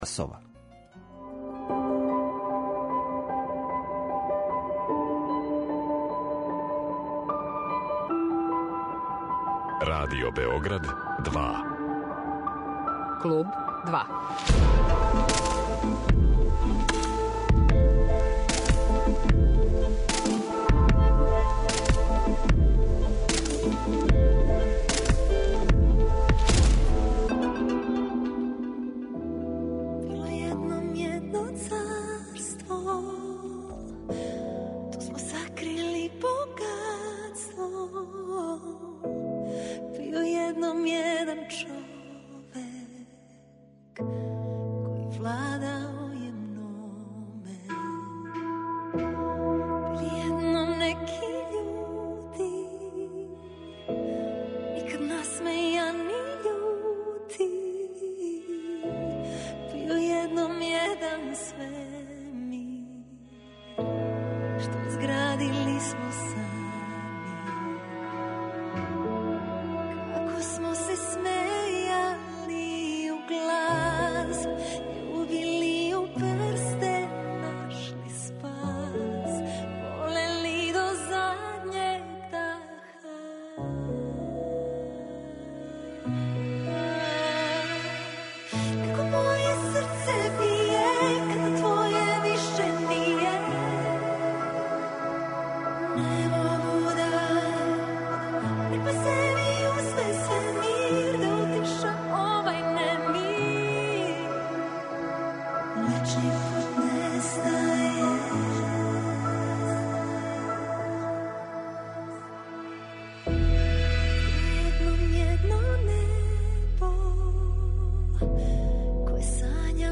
Гост 'Клуба 2' је Александра Ковач